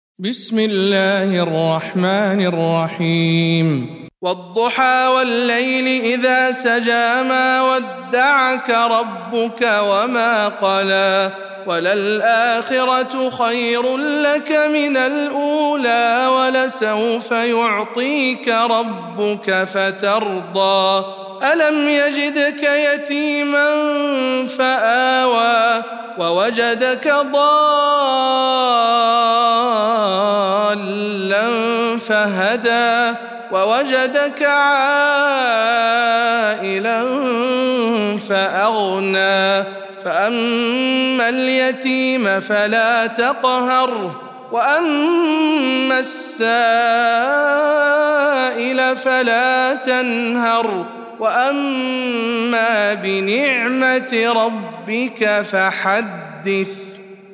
سورة الضحى - رواية رويس عن يعقوب